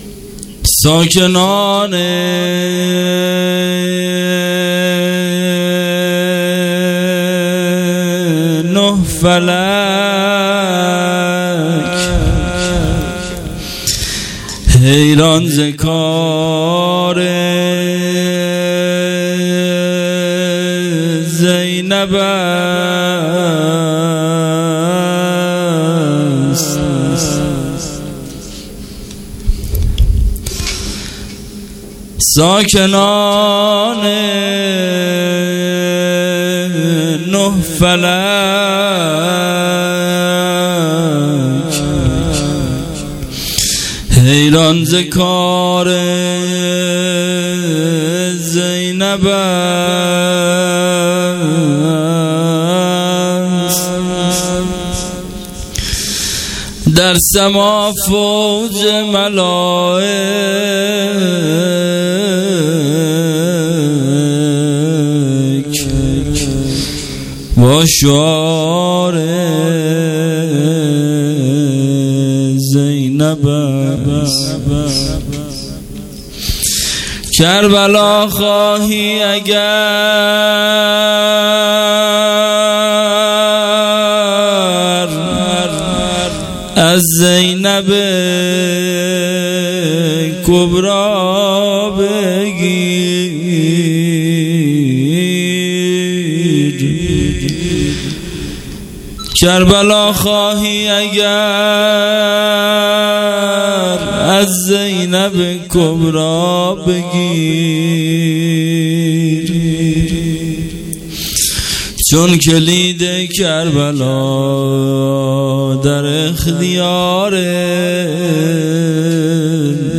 هیئت عاشورا-قم
عزای ایام اسارت اهل بیت(ع) در شام 99